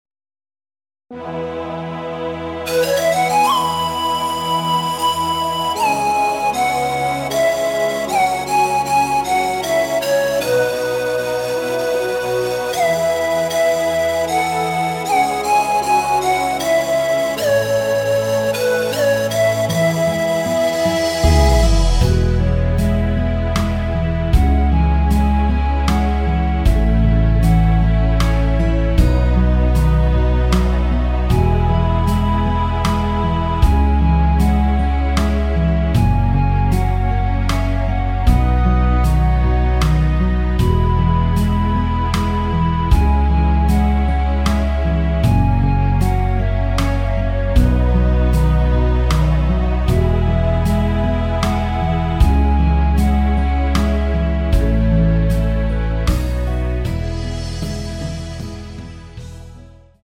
원곡 반주는 박자 맞추기 어려워서 새롭게 편곡 하였습니다.
(+8)키 높인 멜로디 포함된 MR 입니다.(미리듣기 참조)
앞부분30초, 뒷부분30초씩 편집해서 올려 드리고 있습니다.
중간에 음이 끈어지고 다시 나오는 이유는